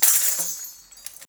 sizzle bells rattle hit.wav